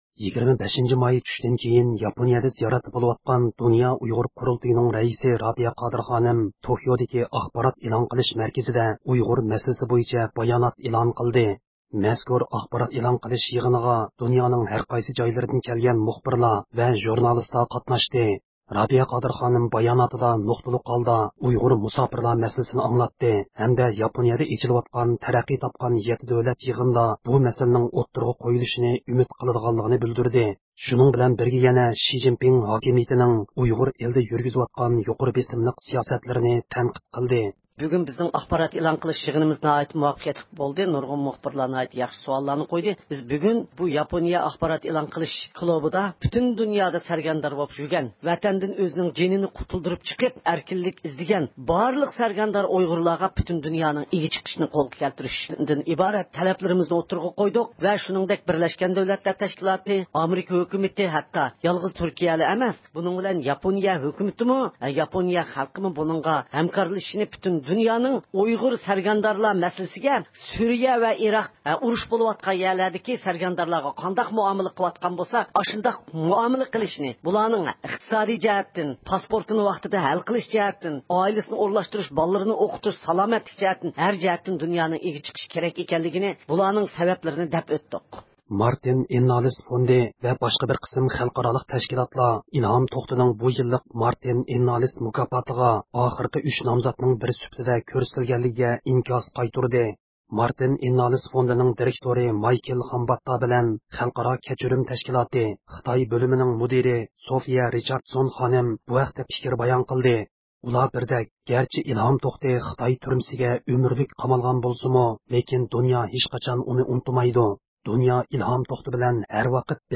erkin-asiya-radiosi.jpgئەركىن ئاسىيا رادىئوسى ئۇيغۇر بۆلۈمى ھەپتىلىك خەۋەرلىرى